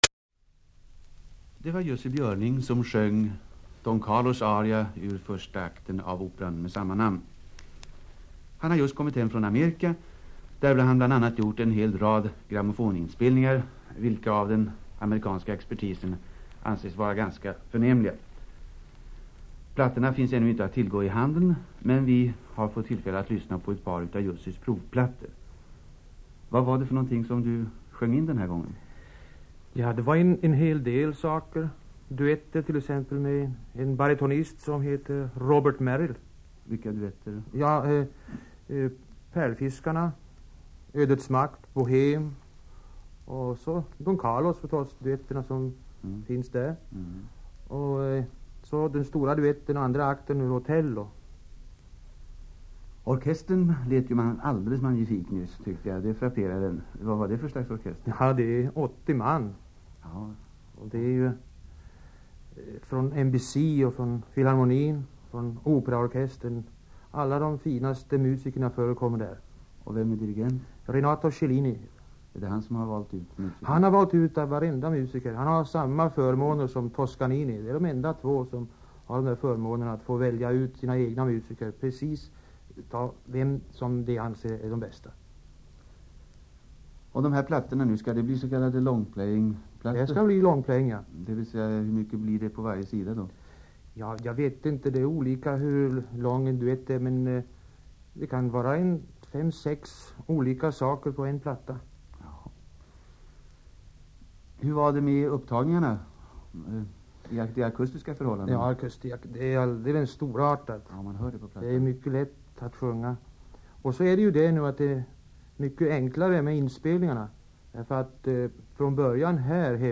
Radio Interviews with Jussi
Interview No. 1. 3:49 mins. In Swedish (51.04)
Recorded 1 April ? 1951. Stockholm, Sveriges Radio.